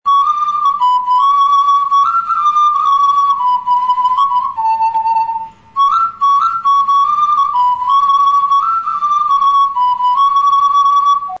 Le soulich (ou Pernk) est une sorte de sifflet en céramique ressemblant à l'ocarina, en forme de une patate douce; il a neuf trous pour les doigts ; sa tonalité est très douce et claire.